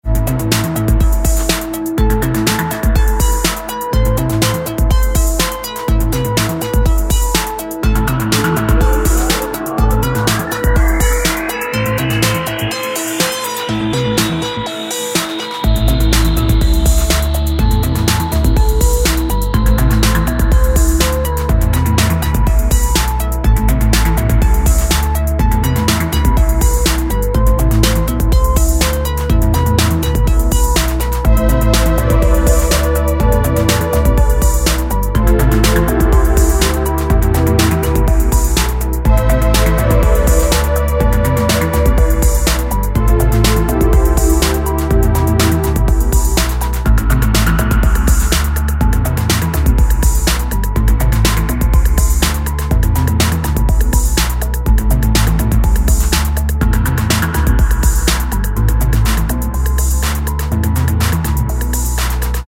Italian new wave band
House Italo